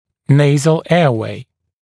[‘neɪzl ‘eəweɪ][‘нэйзл ‘эауэй]носовые воздуховодные пути